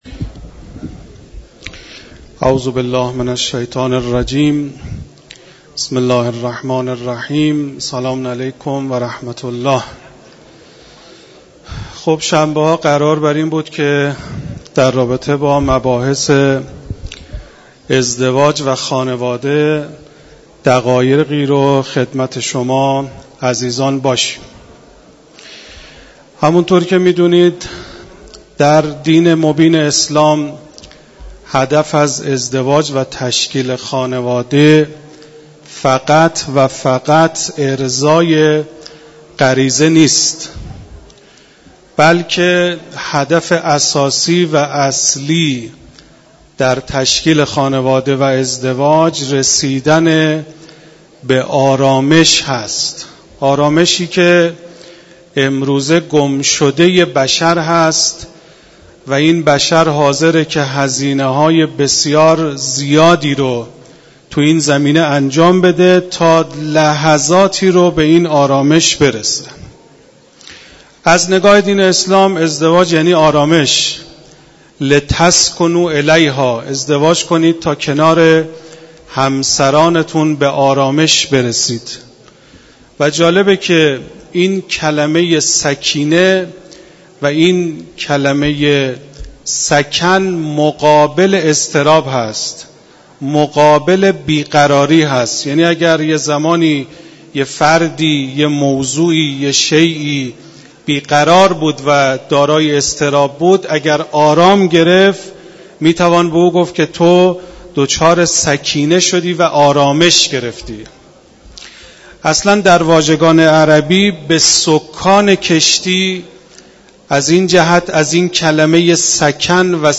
بیان مباحث مربوط به خانواده و ازدواج در کلام مدرس حوزه و دانشگاه در مسجد دانشگاه کاشان